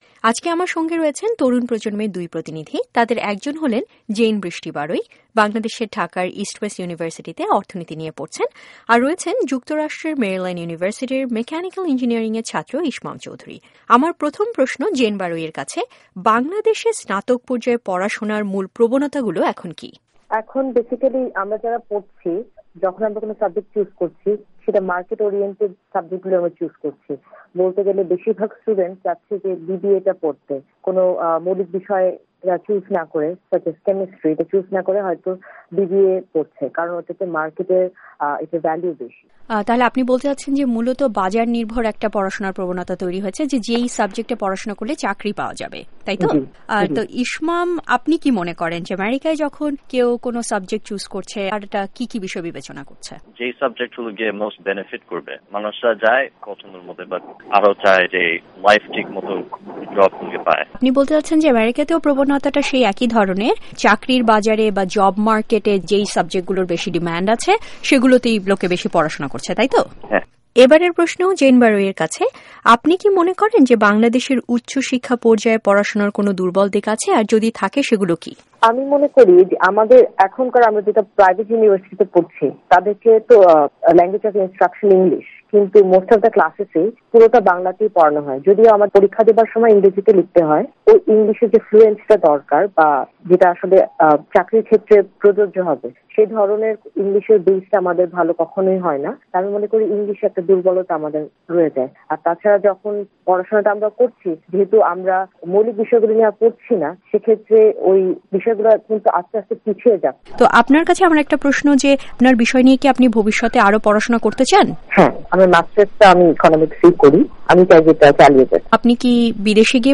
হাইস্কুলের গন্ডী পেরোলেই পরের ধাপ উচ্চশিক্ষা বা স্নাতক পর্যায়ে পড়াশোনা। বাংলাদেশ এবং অ্যামেরিকার স্নাতক পর্যায়ে পাঠরত দুজন শিক্ষার্থীর সঙ্গে আমরা কথা বলেছি, জানতে চেয়েছি তাঁরা তাঁদের শিক্ষা ব্যবস্থা নিয়ে কি ভাবছেন।